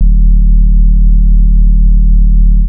Throne Bass 65-01.wav